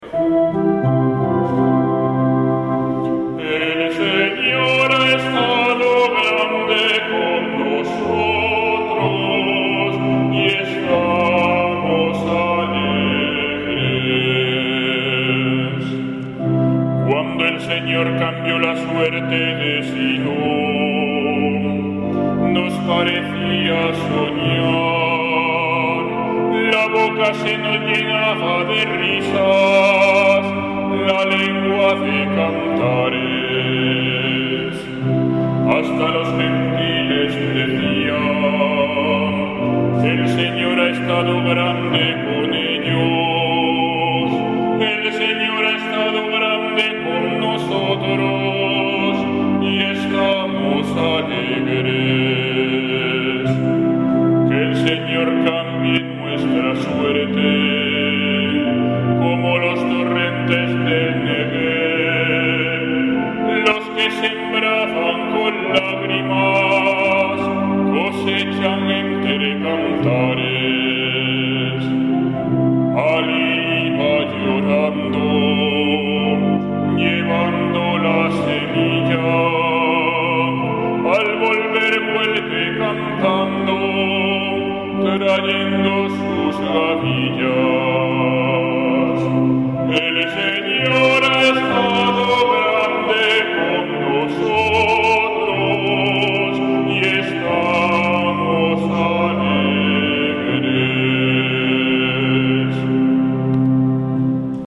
Salmo Responsorial [1.635 KB]
domingocuaresma5salmo.mp3